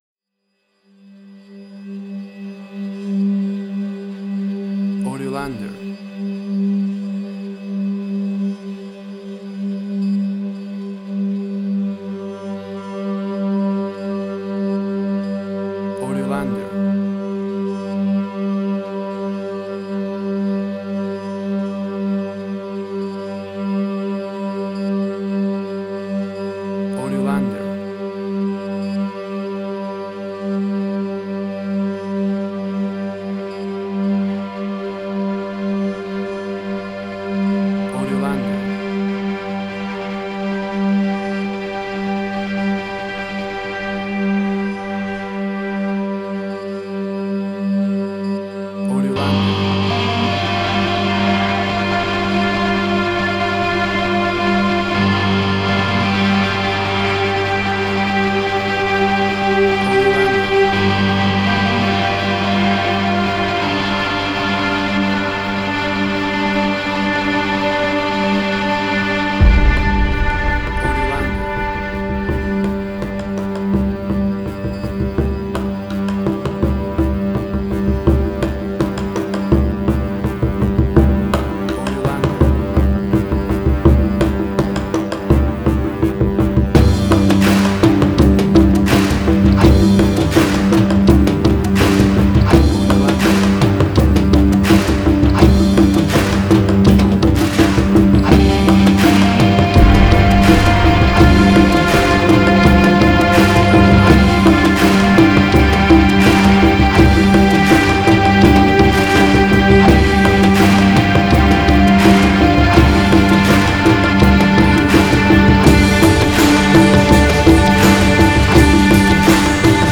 Cinematic Industrial Sci-fi
Tempo (BPM): 83